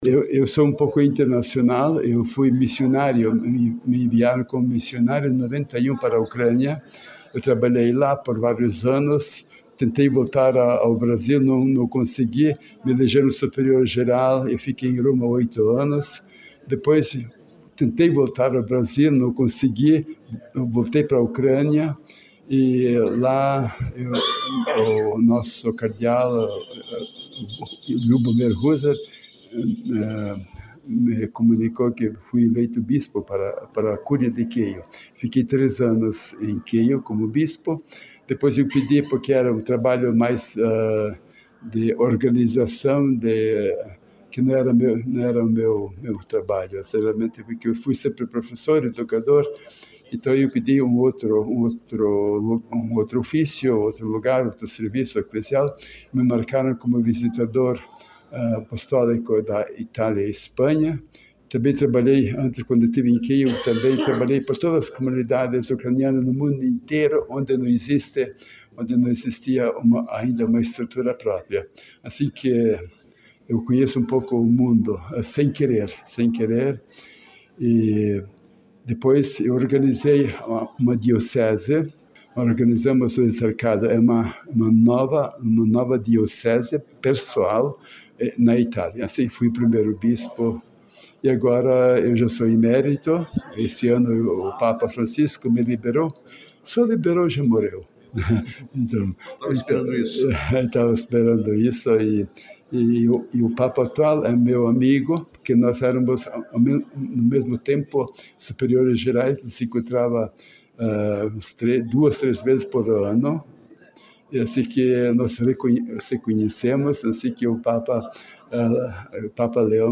O bispo falou ainda que através da igreja visitou o mundo levando a palavra de Cristo por onde passou//